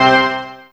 Misc Synth stab 03.wav